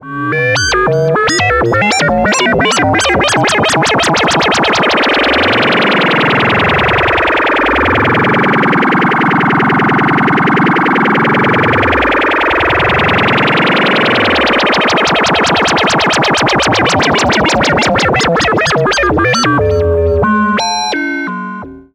Accelerator.wav